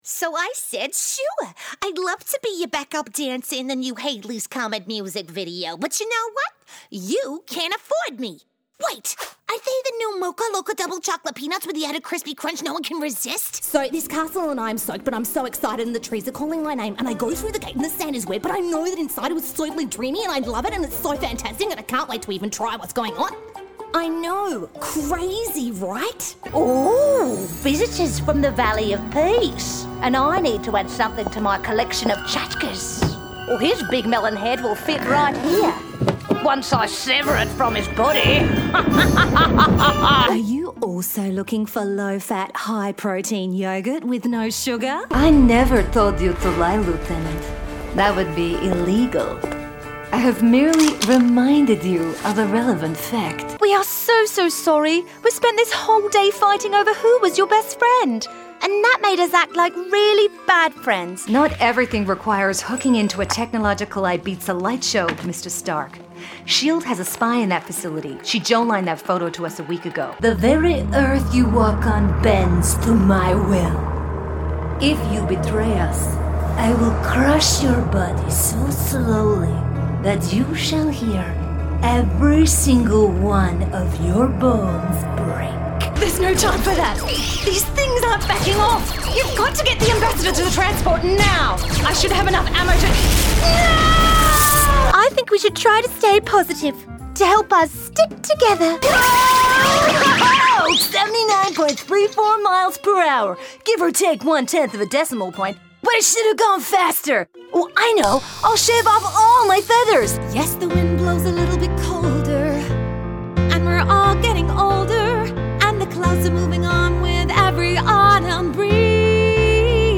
Female
TEENS, 30s, 40s
Australian English (Native)
Bubbly, Character, Children, Confident, Smooth, Versatile
English, Irish, Swedish, Danish, Scottish, Spanish, Persian, Australian, American
Voice reels
Microphone: SE Magento Condenser Mic
Audio equipment: Soundproof Booth, In booth monitor, pop-filter, Focusrite interface, iMac